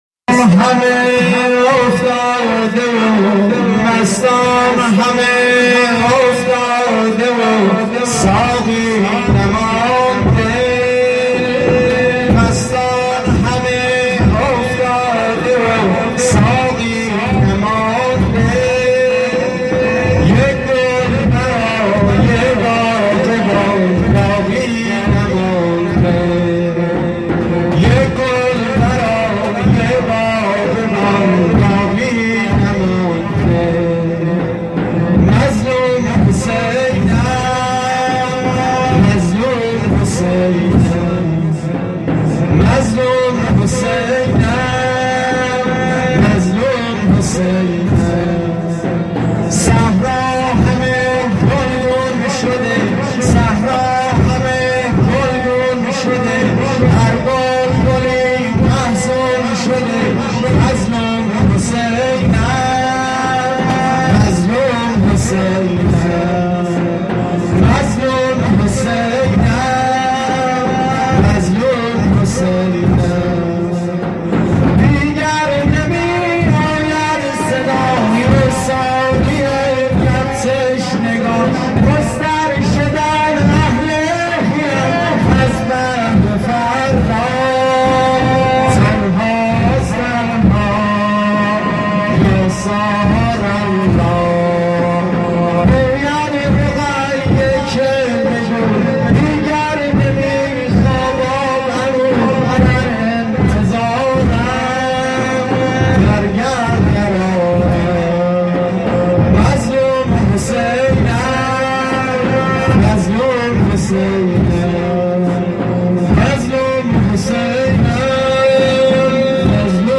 محرم ۹۶(زنجیر زنی)